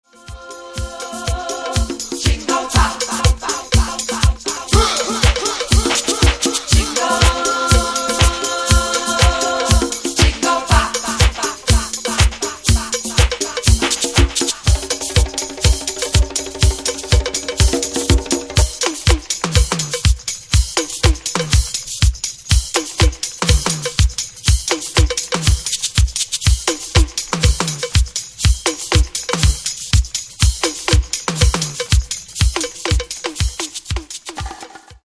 Genre:   Latin Disco